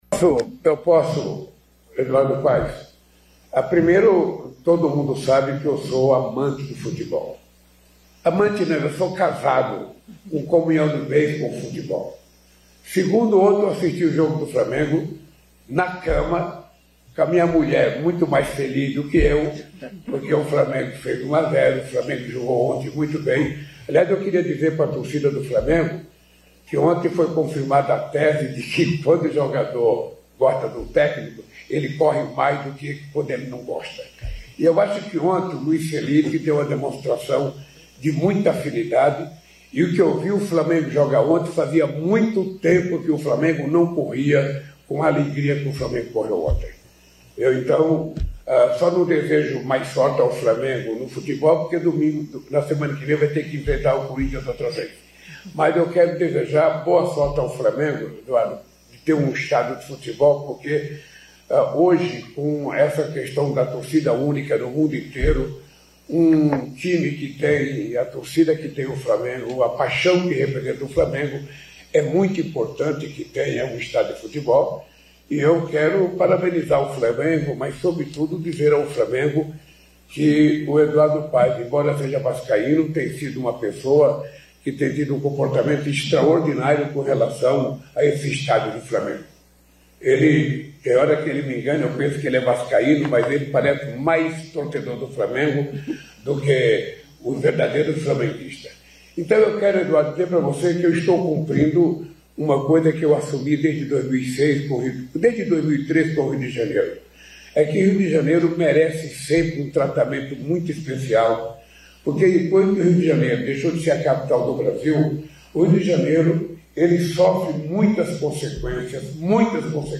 Presidente Lula discursa na Cúpula do Futuro
Durante discurso na Cúpula do Futuro na Organização das Nações Unidas (ONU), em Nova York, nos Estados Unidos, o presidente Lula falou sobre a guerra contra a fome, a crise climática e cobrou mais responsabilidade de instituições.